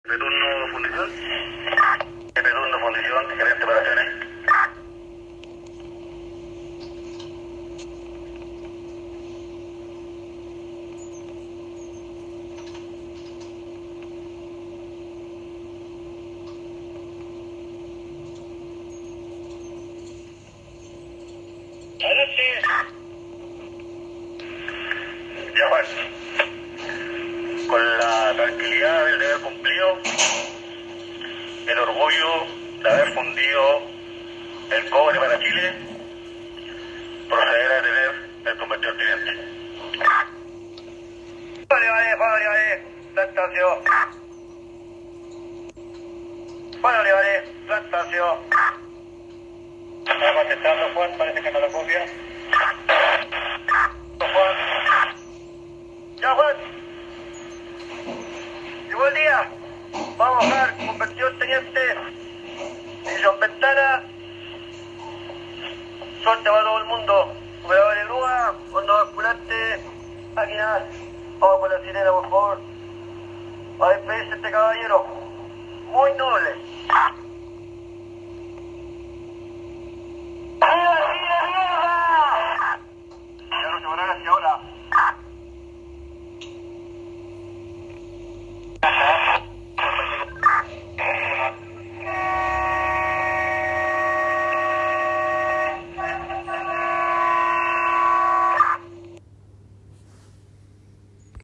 PUCHUNCAVÍ.- A esta hora, se realiza la ceremonia que da inicio al cierre gradual de la Fundición Ventanas de Codelco en la comuna de Puchuncaví, tras 58 años de funcionamiento.